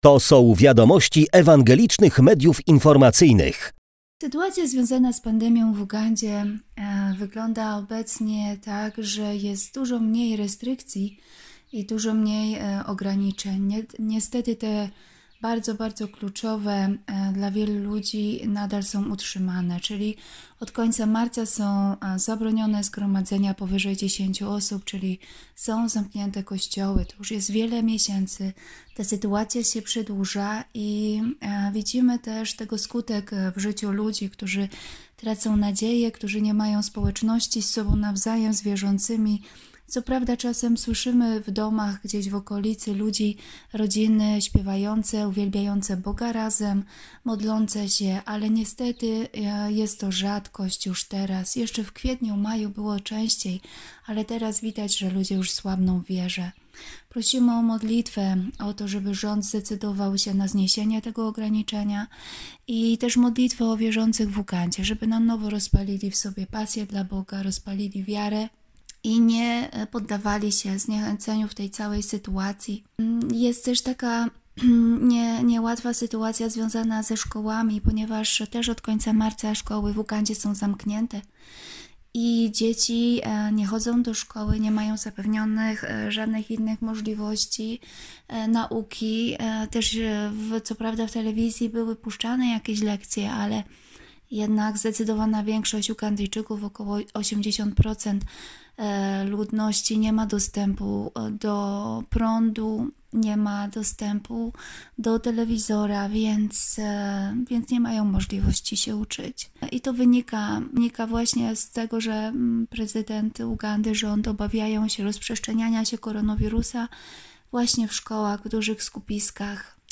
polska misjonarka i sytuacji w Ugandzie.